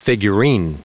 Prononciation du mot figurine en anglais (fichier audio)
Prononciation du mot : figurine